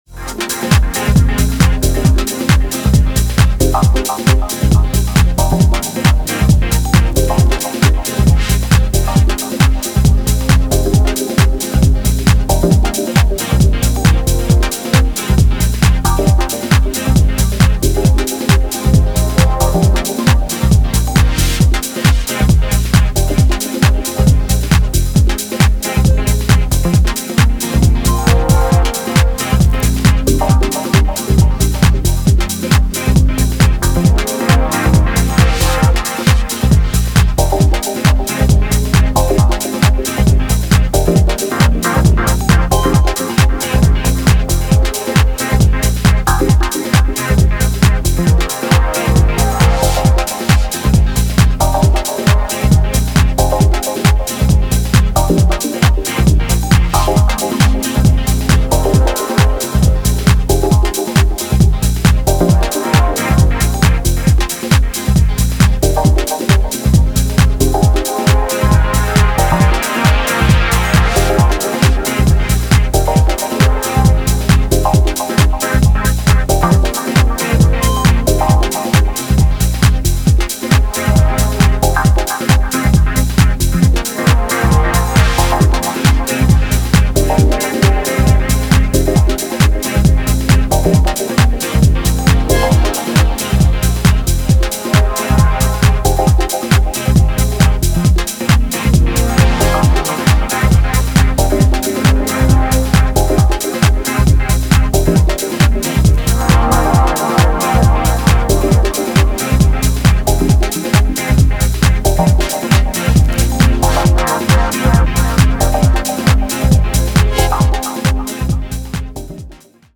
疾走するグルーヴが美麗シンセレイヤーをまとったモメンタムなフロア・フィラー
深い没入感に加えクラシカルなディープ・ハウスのエネルギーも感じられますね。